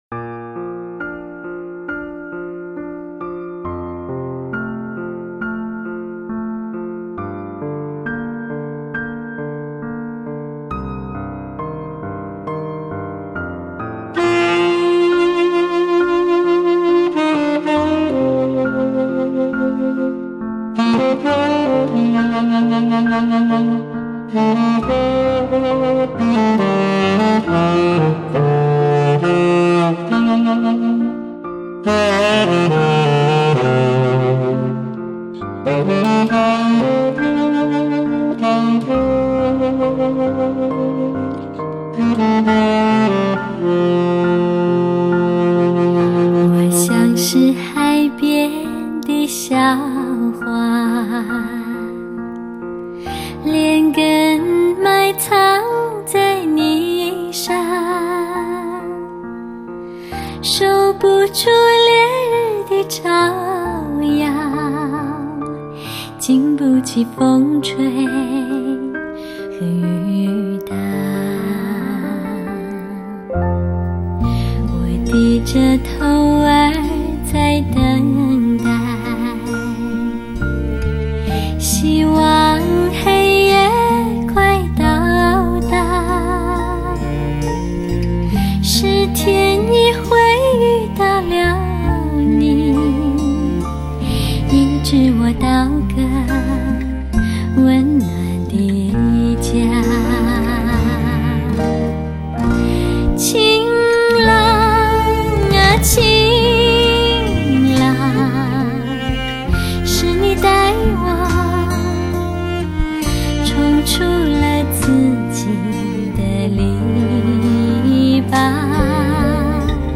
她的歌声甜中带美，柔中又带质感，似一缕春风，似清香的茉莉花沁人心田，犹如天女唱出天籁之梵音。